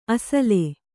♪ asale